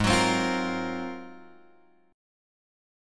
G#13 chord {4 3 4 1 1 1} chord